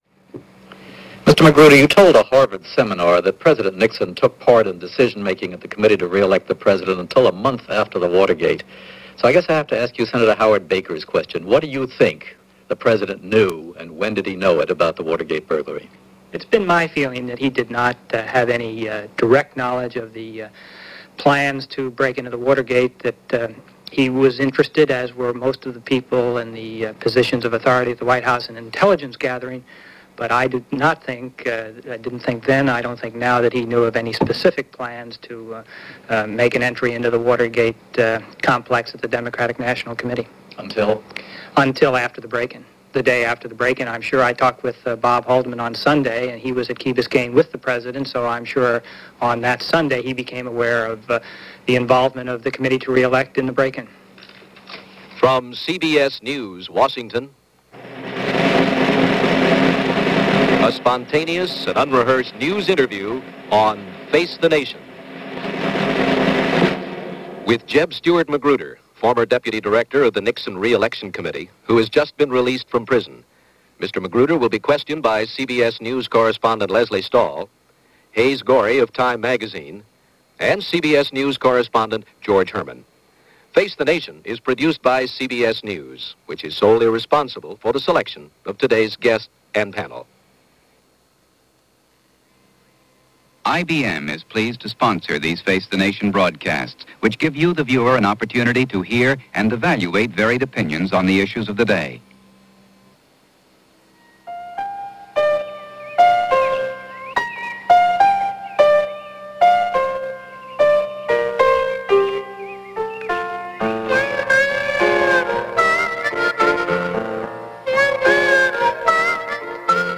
Jeb Magruder: Watergate - Face The Nation - 1975 - Weekend Talkshows Past - Magurder, a figure in the Watergate scandal, is interviewed.